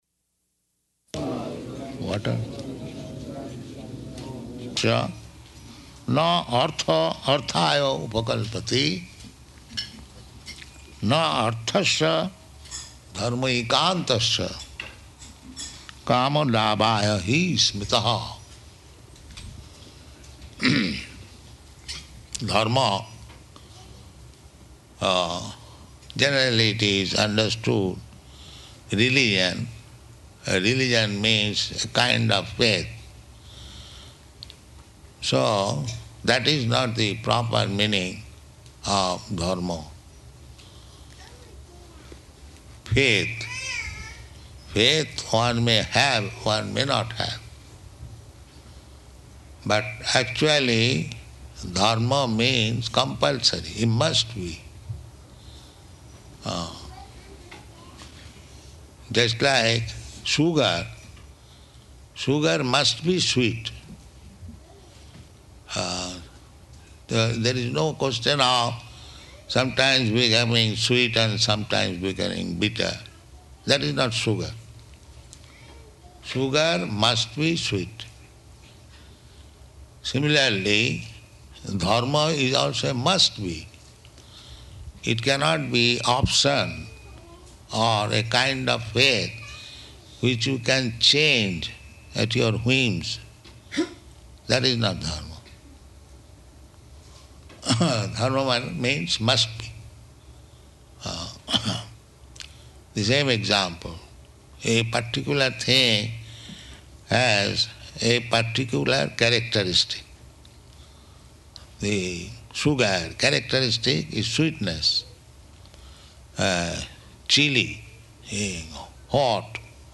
Śrīmad-Bhāgavatam 1.2.9 University Lecture